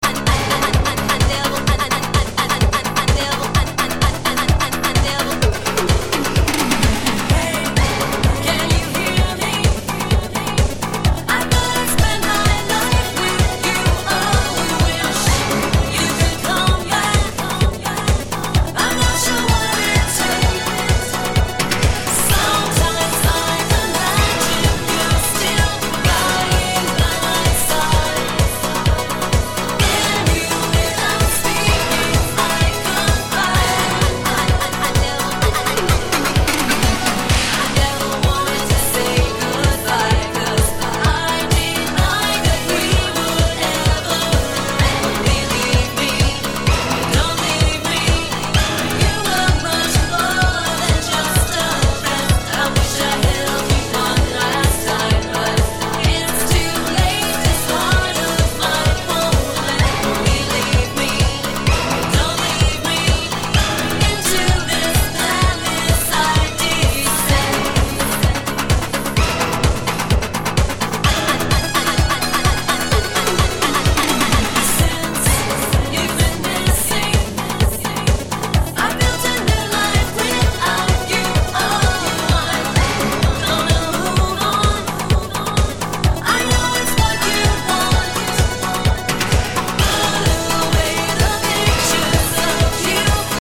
Girl duo like Bananarama, Abba, Tapps, & The Flirts.
B is a romantic Eurobeat style track in... more...